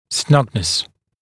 [‘snʌgnəs][‘снагнэс]плотность прилегания (напр. дуги в пазе брекета)